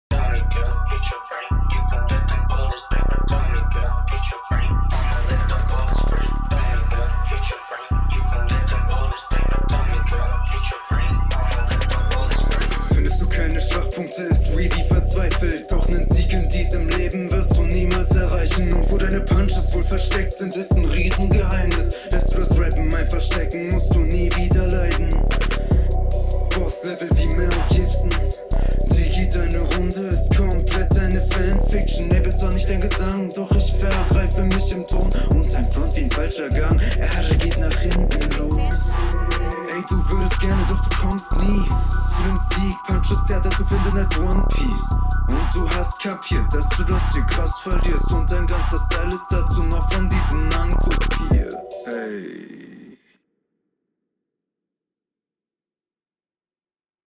Flow gut, Mix auch, Punches nice, Reime gehen klar
Deutlich cooler gerappt und gereimt als in der anderen Runde, der Beat scheint dir mehr …